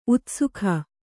♪ utsukha